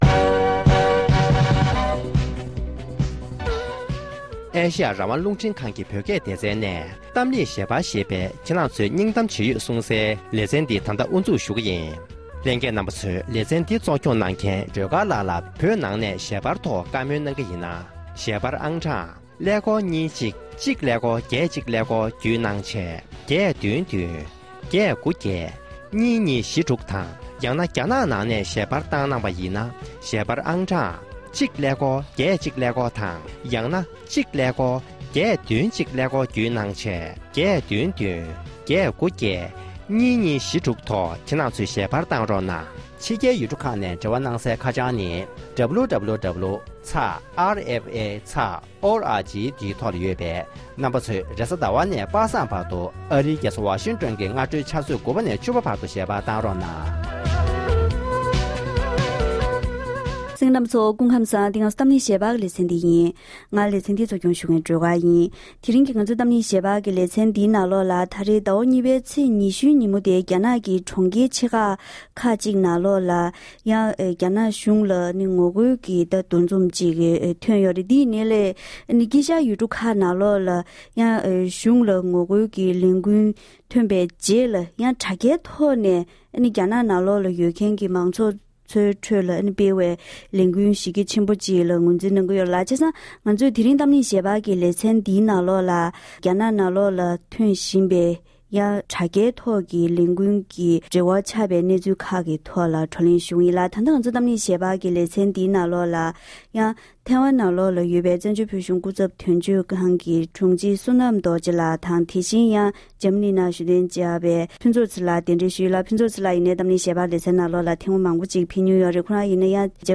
གཏམ་གླེང་ཞལ་པར་